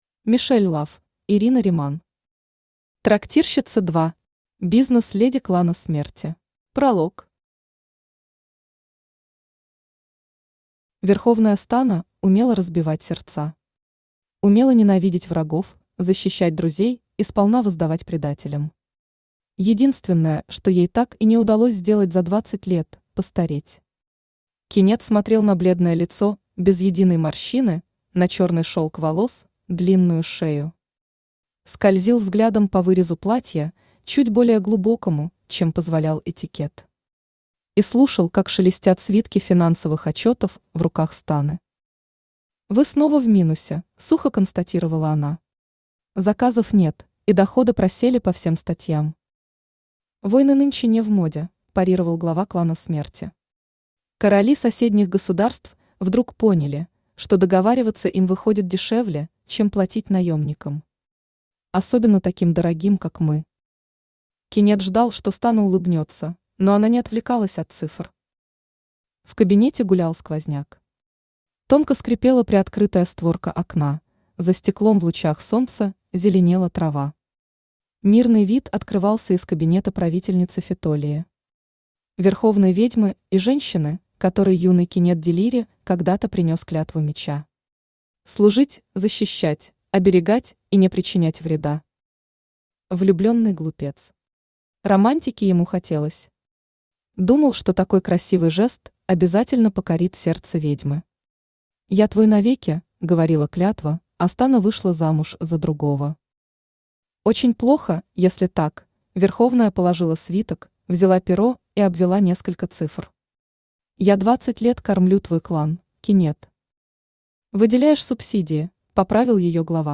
Аудиокнига Трактирщица 2. Бизнес-леди Клана смерти | Библиотека аудиокниг
Бизнес-леди Клана смерти Автор Мишель Лафф Читает аудиокнигу Авточтец ЛитРес.